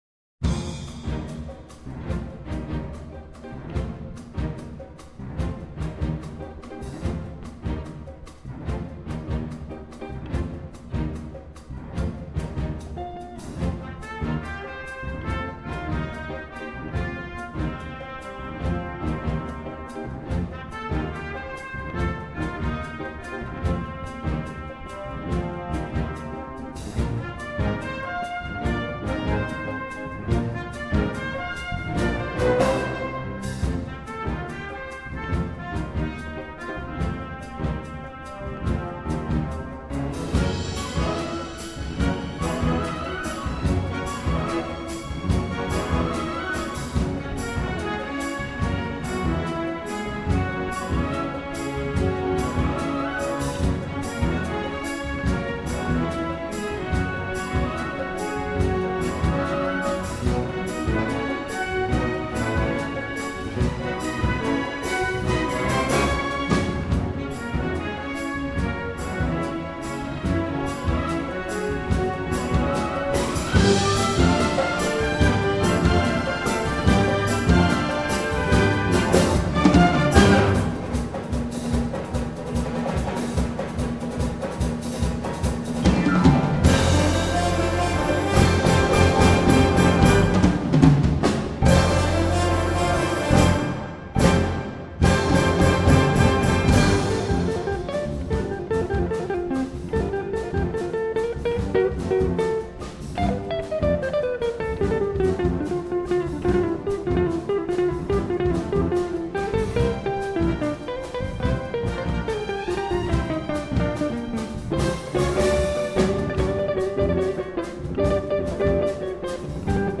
以略带些美国爵士风格的方式，轻松愉快地演绎了这些曲目。